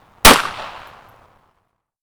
laukaus01c.wav